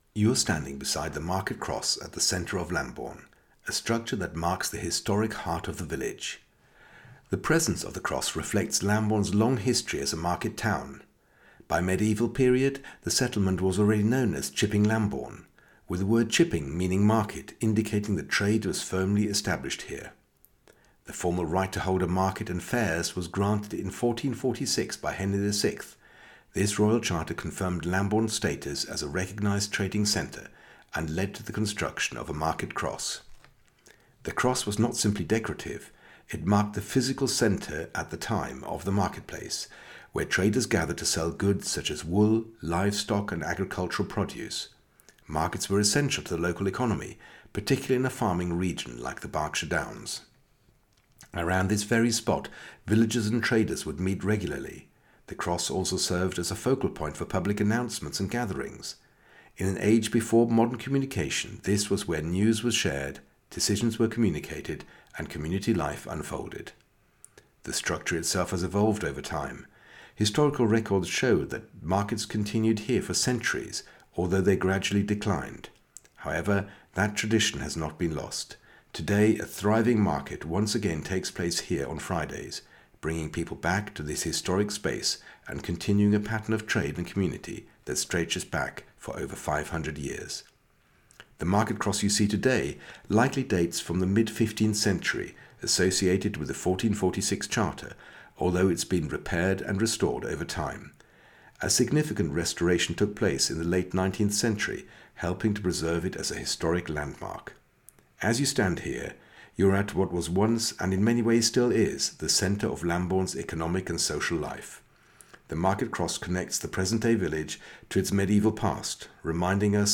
Scan the code and you are taken straight to a short, three minute audio guide.
Just a clear human voice explaining what you are looking at, as if a knowledgeable local guide were standing beside you.